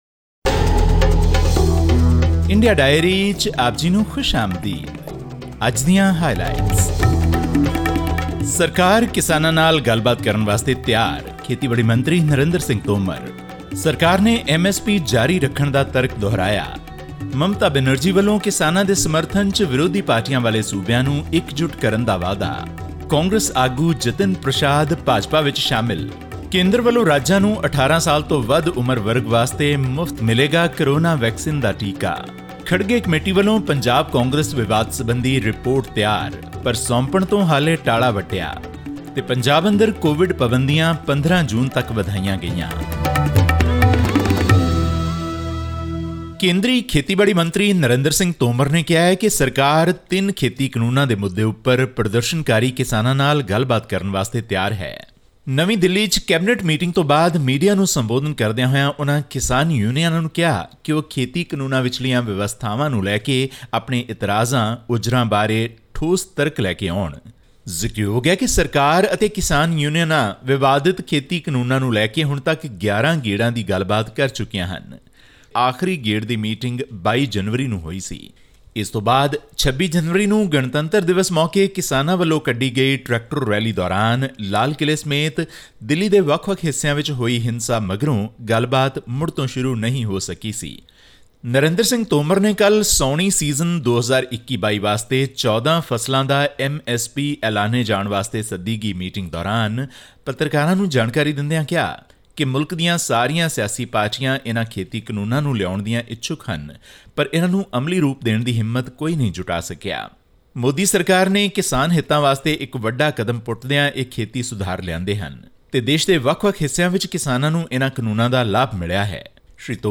All this and more in our weekly news segment from India.